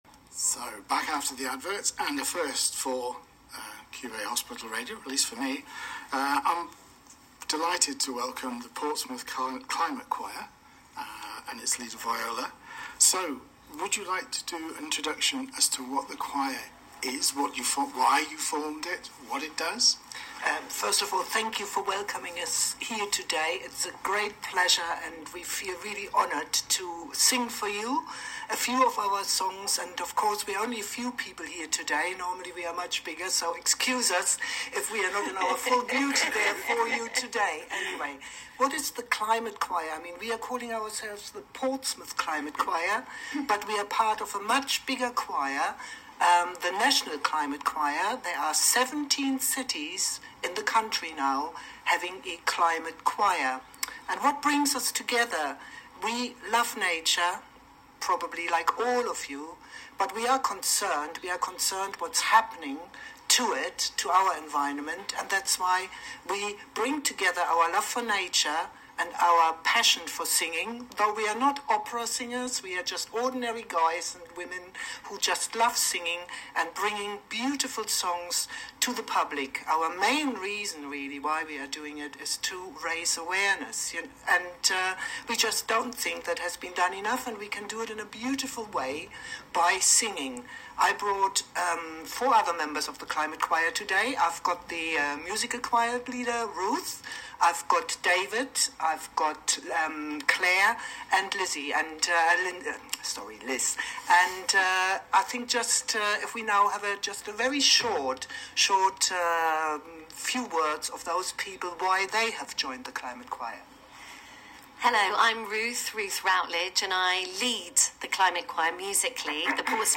Portsmouth Climate Choir introduces the choir and sings three of our songs on the programme